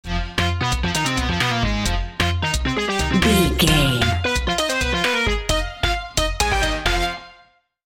Aeolian/Minor
Fast
bouncy
bright
cheerful/happy
funky
groovy
lively
playful
uplifting
synthesiser
drum machine